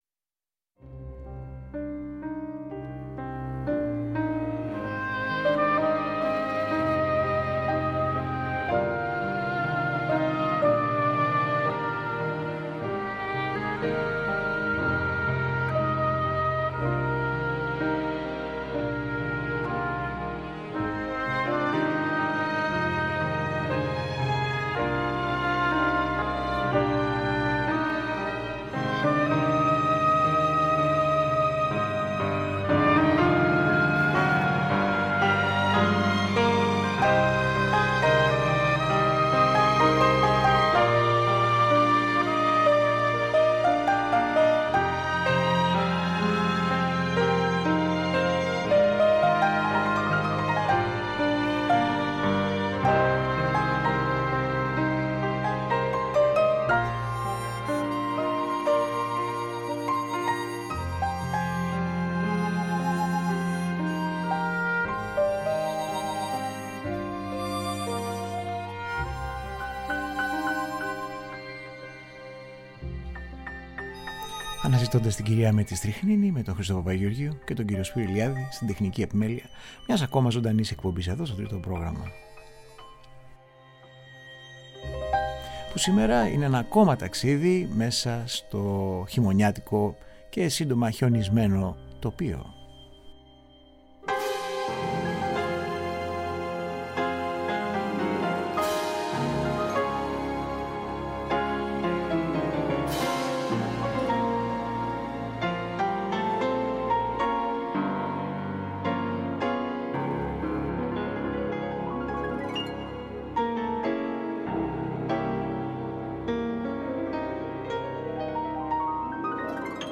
Μουσικές για ιστορίες χιονιού, κρύου και επιβίωσης μέσα στην πανέμορφο όσο και κρυφά θανάσιμο παγερό πρόσωπο της φύσης.
Κινηματογραφικη Μουσικη